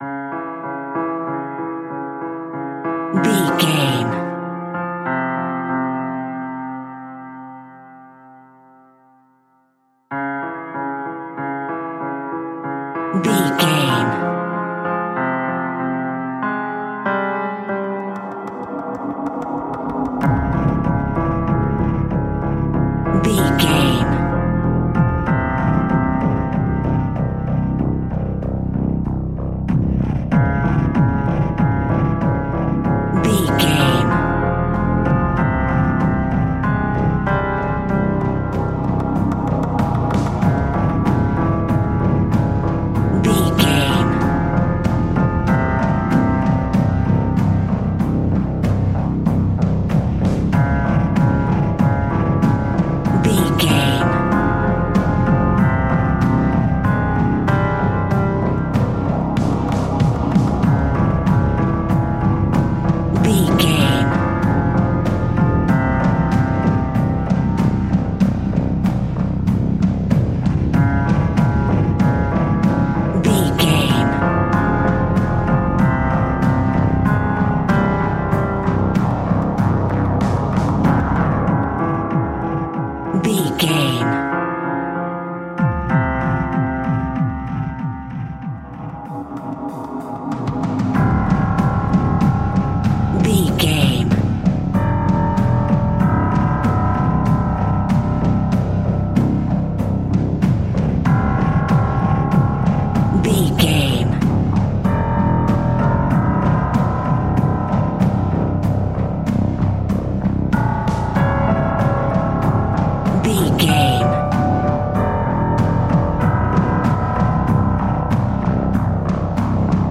Aeolian/Minor
C#
scary
ominous
dark
haunting
eerie
piano
drums
synthesiser
creepy
horror music
Horror Pads
Horror Synths